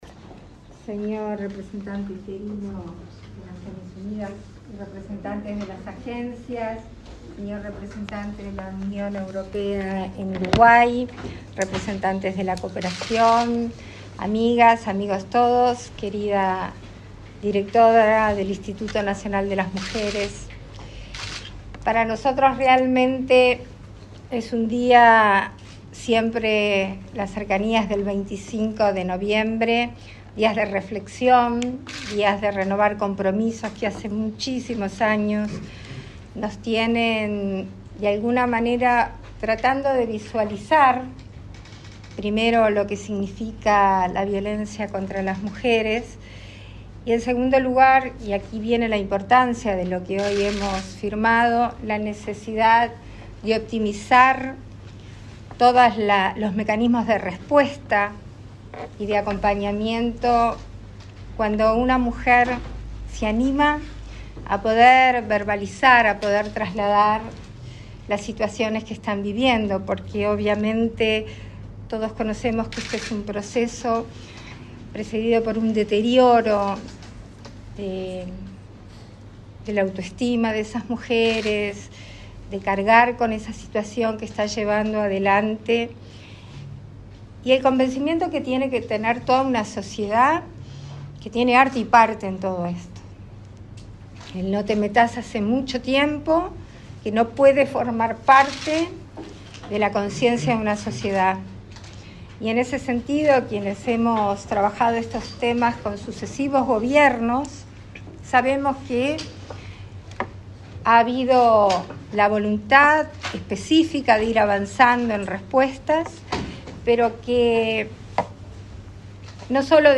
Palabras de la presidenta en ejercicio, Beatriz Argimón
Este viernes 24 en la Torre Ejecutiva, la presidenta en ejercicio, Beatriz Argimón, participó de la firma del proyecto "Iniciativa para prevenir y mejorar la respuesta a la violencia de género y generaciones en Uruguay", que recibirá apoyo de la Unión Europea y de Naciones Unidas en Uruguay y se ejecutará en coordinación con el Instituto Nacional de las Mujeres.